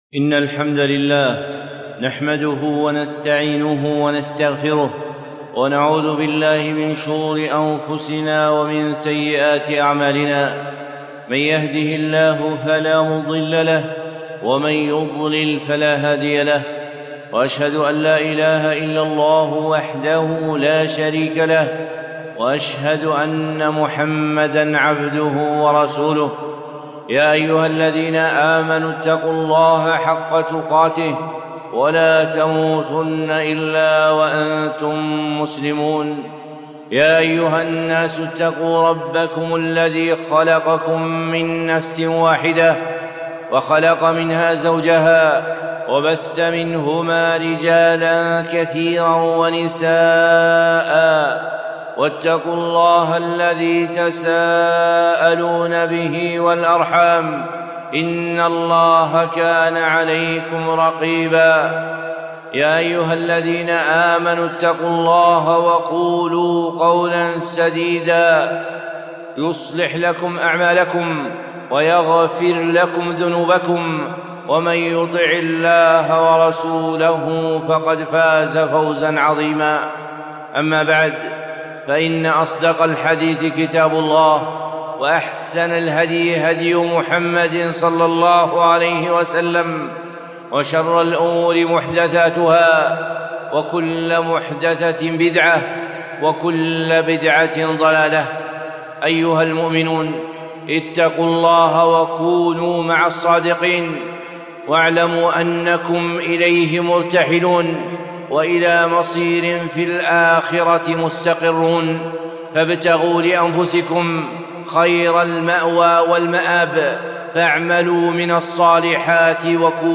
خطبة - الاقتراب من الله